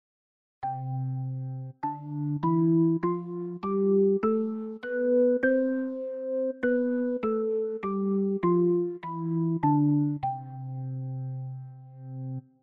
018：パーカッシブオルガン（Percussive Organ）
ＧＭ音源プログラムチェンジの１８番は、パーカッシブ・オルガン（Percussive Organ）の音色です。
パーカッシブ・オルガンは、通常のオルガンの音色に「コツッ」といったようなアタック音を加えた音色です。